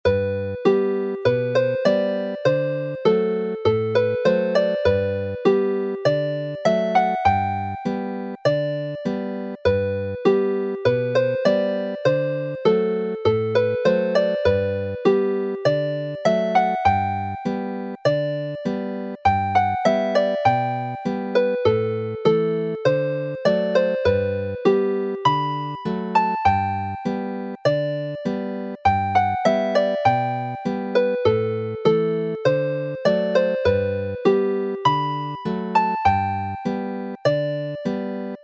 Although it is named as a jig, the music is written and played as a polka.
Chwarae'n araf
Play slowly